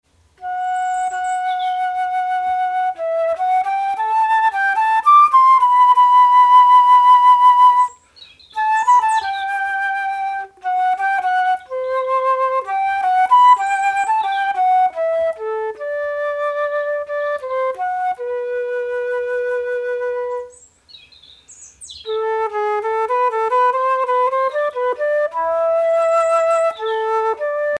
Valse
danse : valse
danse : boston (valse américaine)
Concert donné en 2004
Pièce musicale inédite